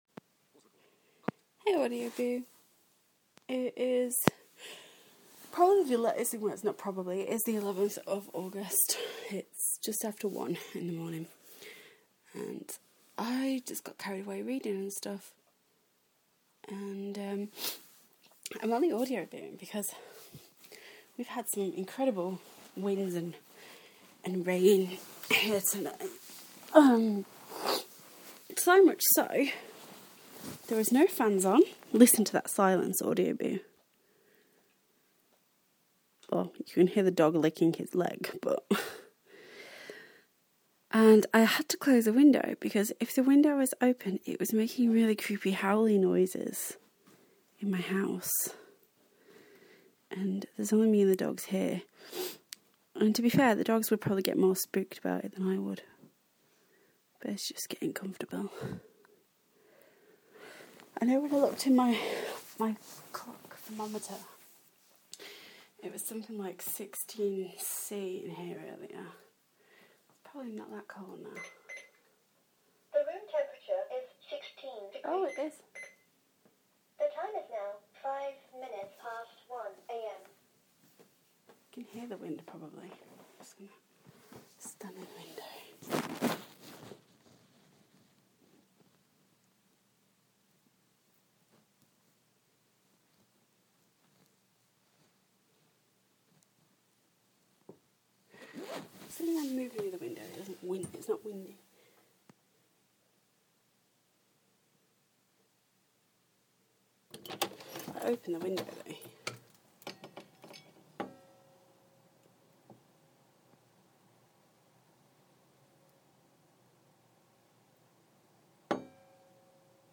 Creepy wind sound